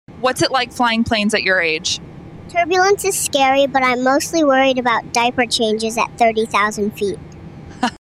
You Just Search Sound Effects And Download. tiktok sound effects meme Download Sound Effect Home